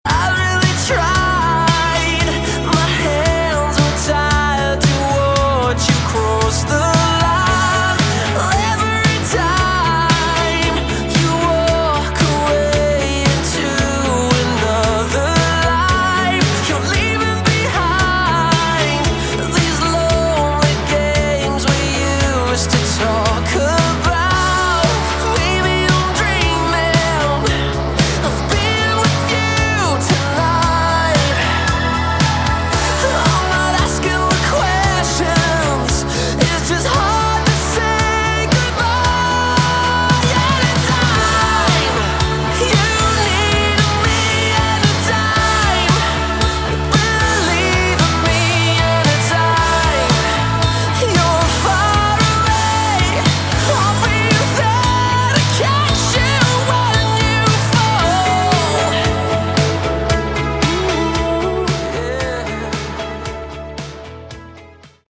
Category: Melodic Rock / AOR
guitar, vocals
keyboards, vocals
bass